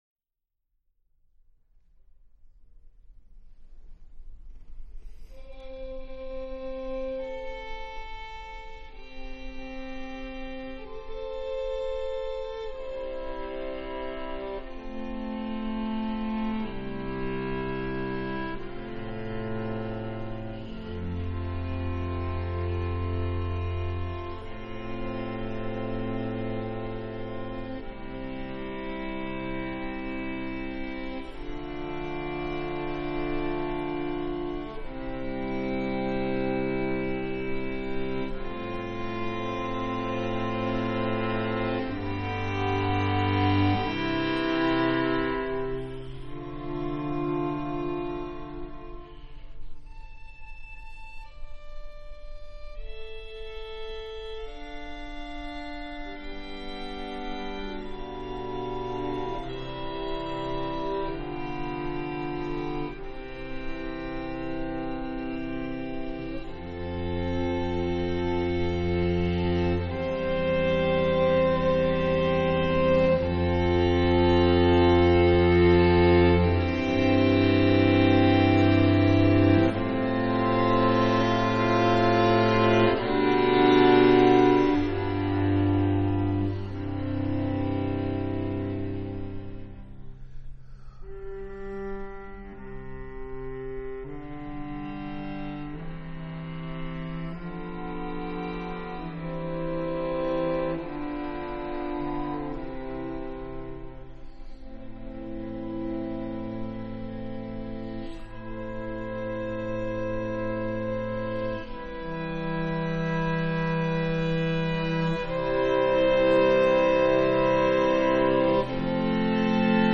quartetto.mp3